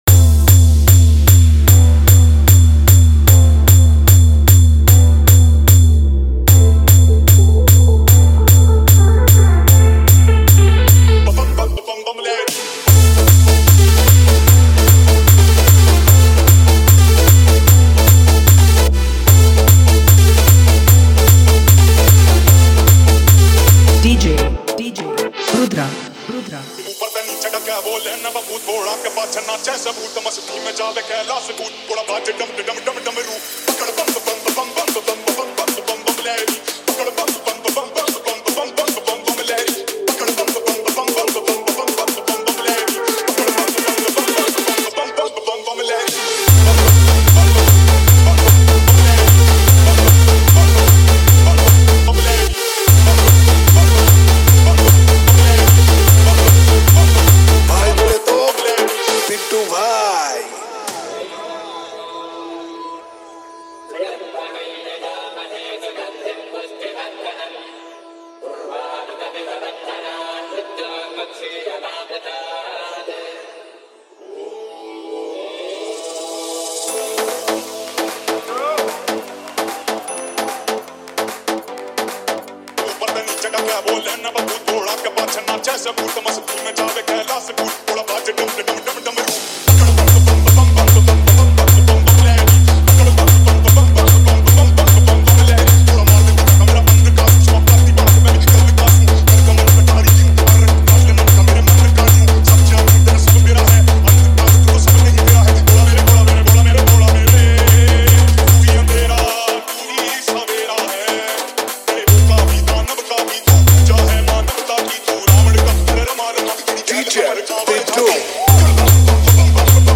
Humming Dj Bhajan Songs Download